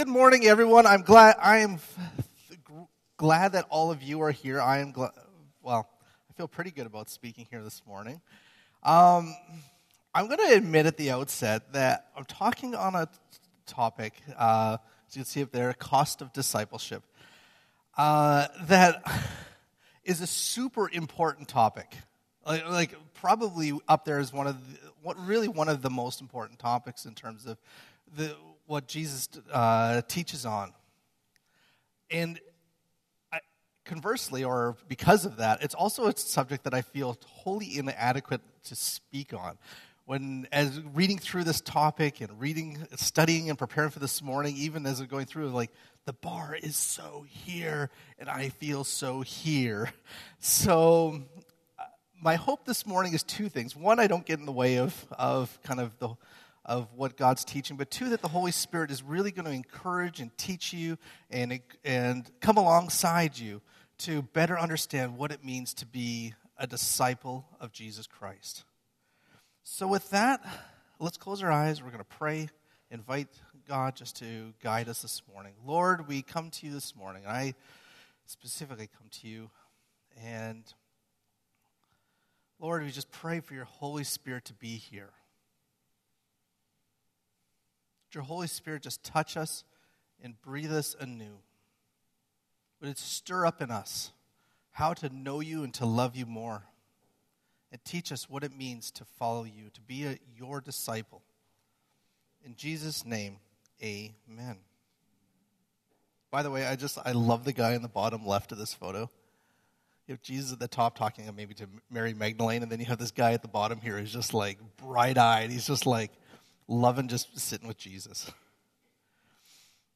This sermon is based on Luke 14:25-34